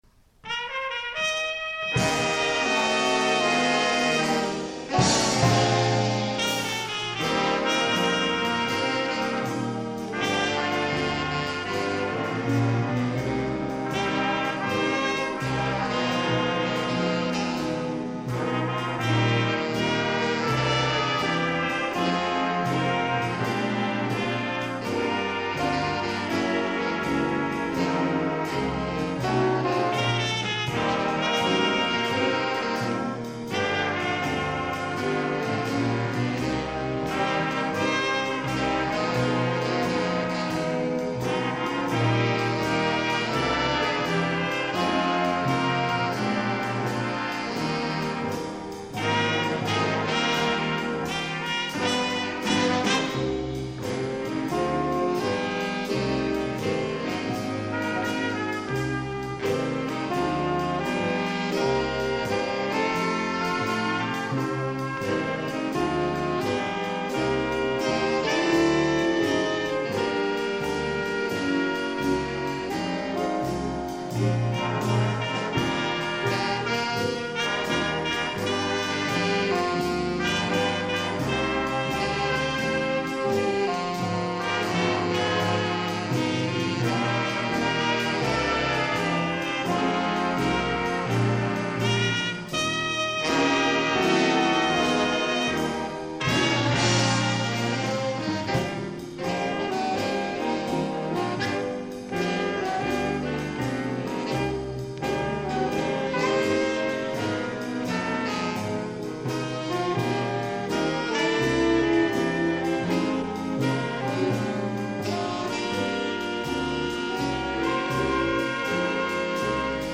Il fonde en 1982 un big band avec des élèves de fin d'études de l'ENM, parmi lesquels la future vedette du jazz français Sylvain Beuf.
Voici les enregistrements de 1983 et 1985, numérisés en 1997 à partir des cassettes.
Enregistré sur cassette en concert, le 29 avril 1983 à l'Auditorium Marcel Pagnol de Bures-sur-Yvette (Essonne).